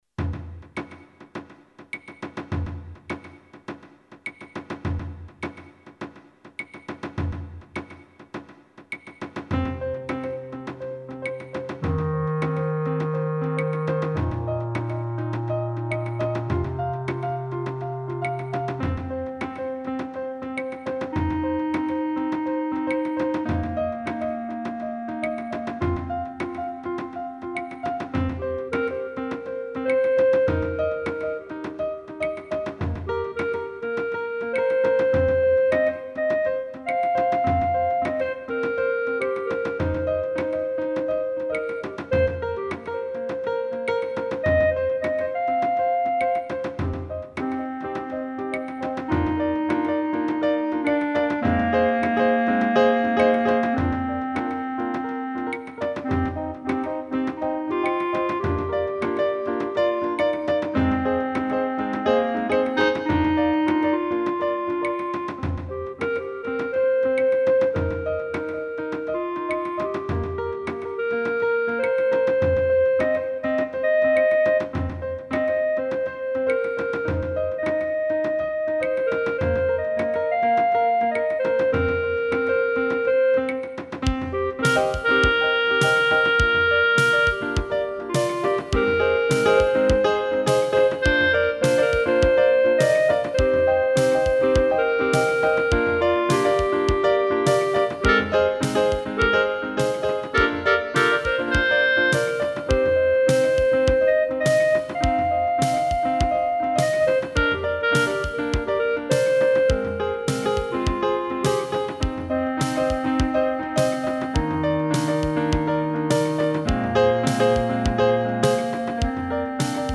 Tempo: 53 bpm / Datum: 06.04.2017